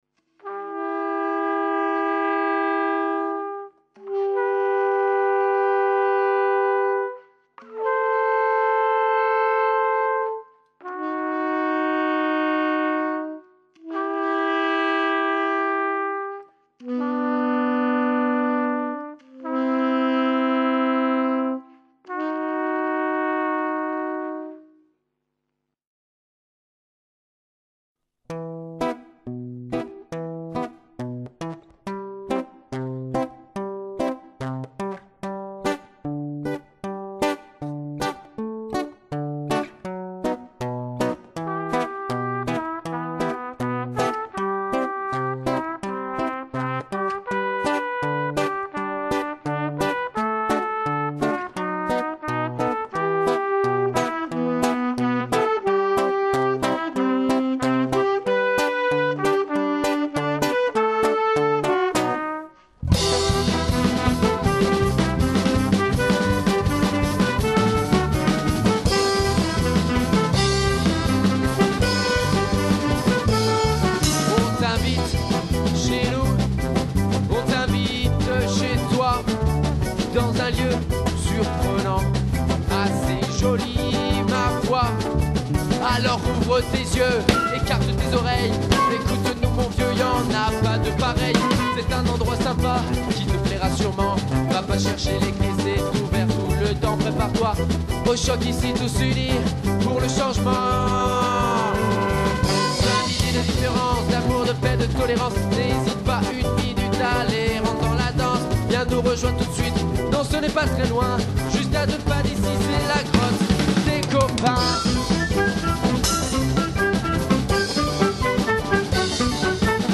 Petit morceau made in La Chambre